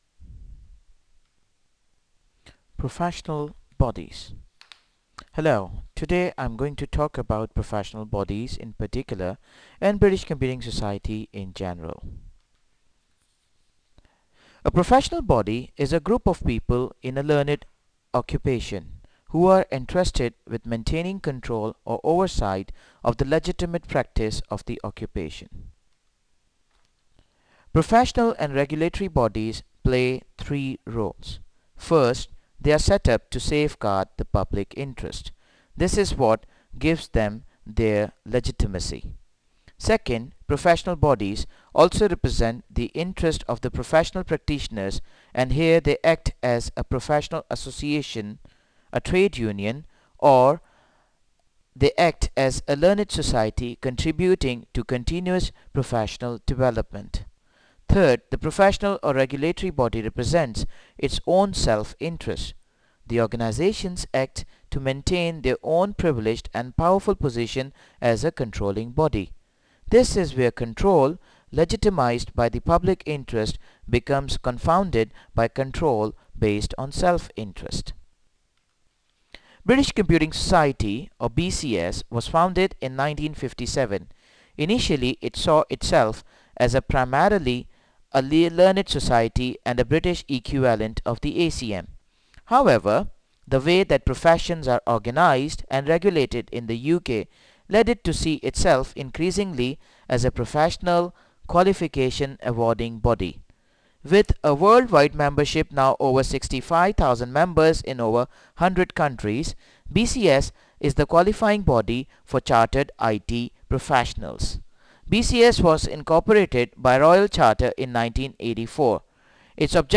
BCS lecture audio